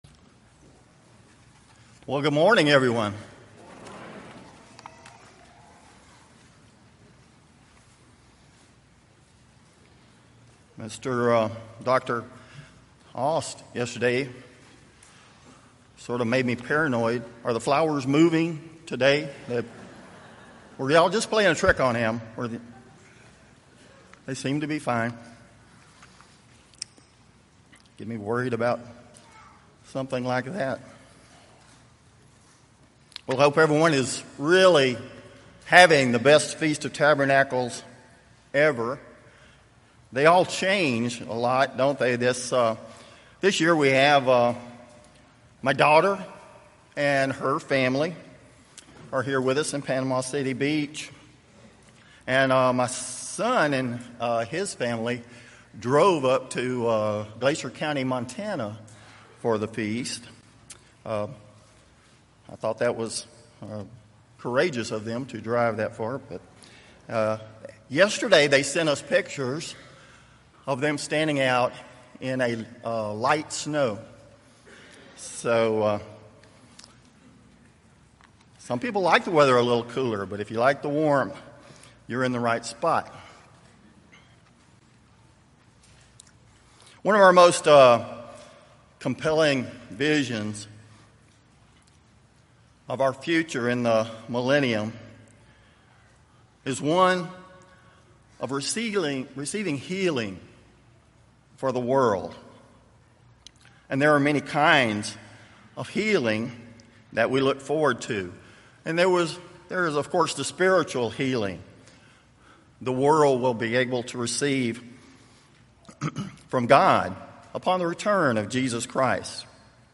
This sermon was given at the Panama City Beach, Florida 2018 Feast site.